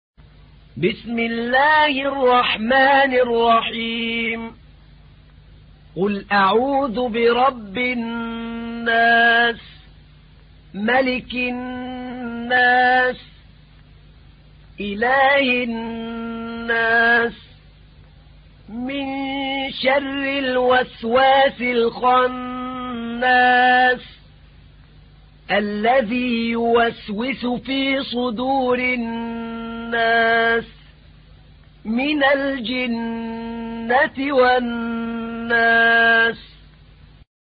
تحميل : 114. سورة الناس / القارئ أحمد نعينع / القرآن الكريم / موقع يا حسين